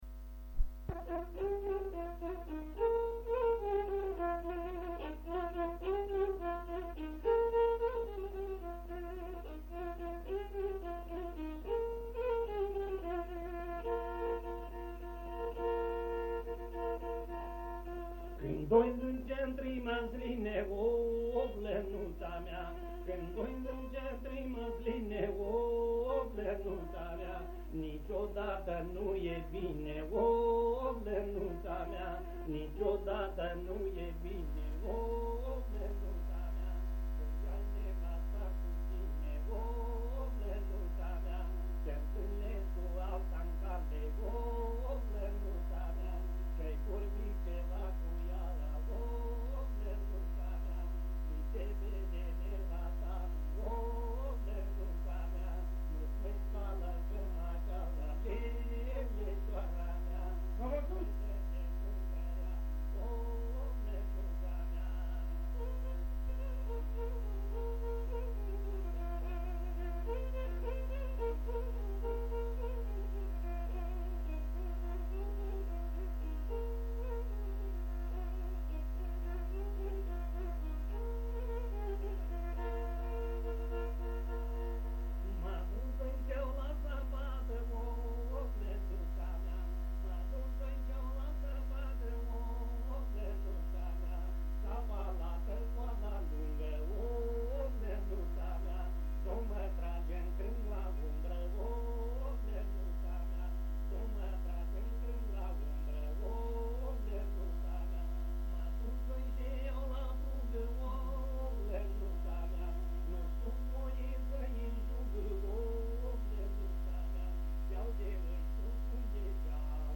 Frumos kînćik rumîńesk, đin Rabrova, sat rumîńesk în Bugariĭe! Snimuit pi lînga anu 1980.